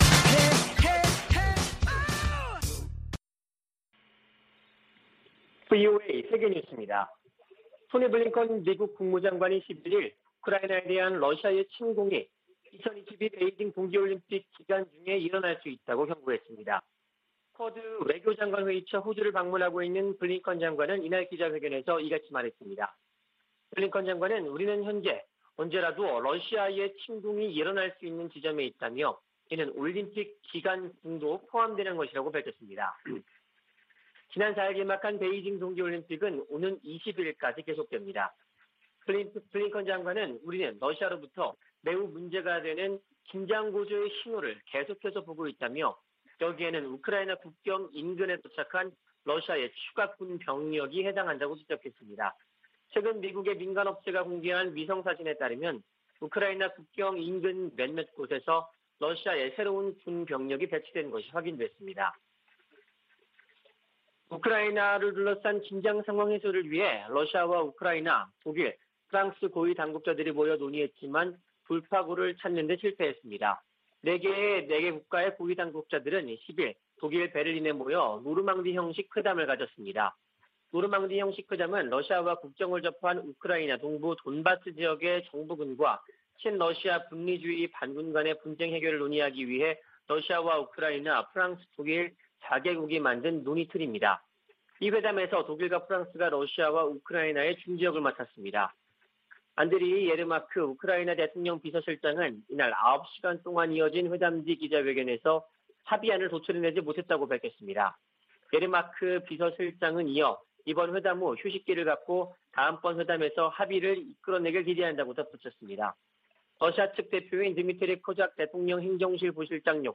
VOA 한국어 아침 뉴스 프로그램 '워싱턴 뉴스 광장' 2021년 2월 12일 방송입니다. 토니 블링컨 미 국무부 장관은 4개국 안보협의체 '쿼드(Quad)'가 공통 도전에 대응하고 번영 기회를 찾는 것이 목표라고 밝혔습니다. 미국은 북한의 잘못된 행동을 막기 위해 유엔 회원국들이 한 목소리를 내야 한다고 밝혔습니다. 미국과 한국이 지난 2018년 이후 중단된 대규모 실기동 연합훈련을 재개해야 한다고 미 해병대사령관이 밝혔습니다.